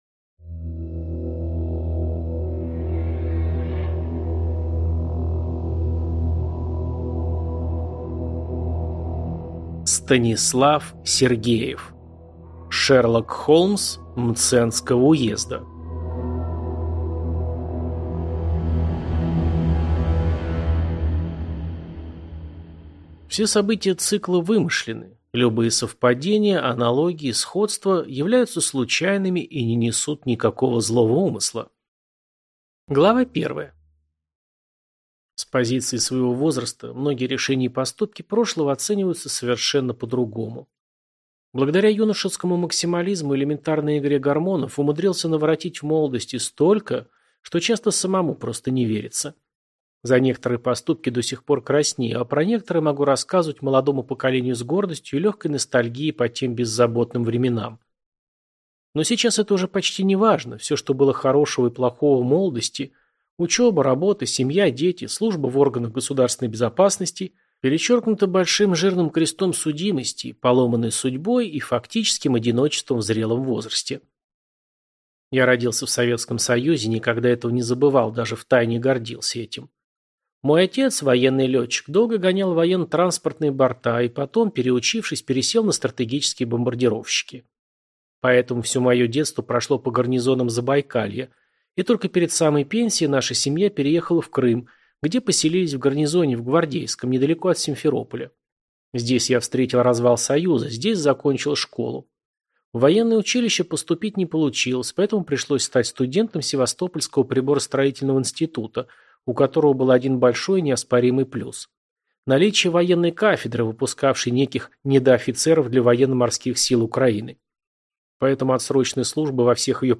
Аудиокнига Шерлок Холмс Мценского уезда | Библиотека аудиокниг